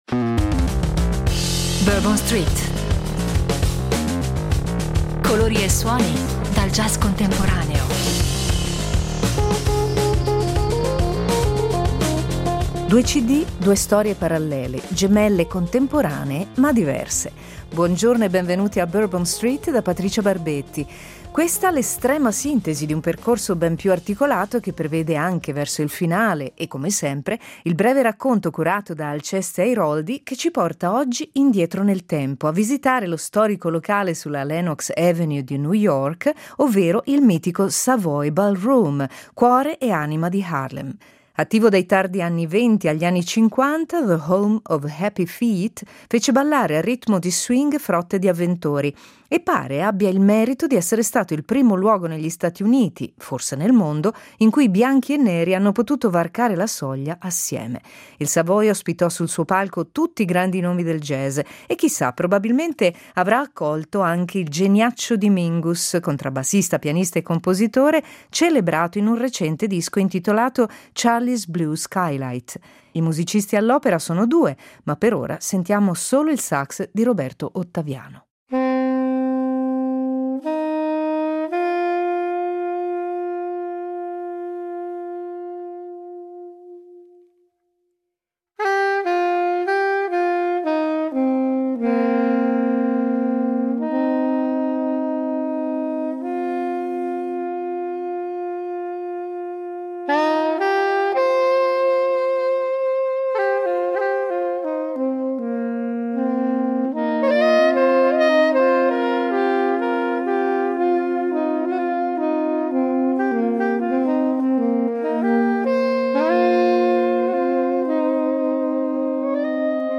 Vecchio e nuovo insieme, spinta verso il futuro coniugata con il più totale rispetto della propria storia: colori e suoni dal jazz contemporaneo insomma.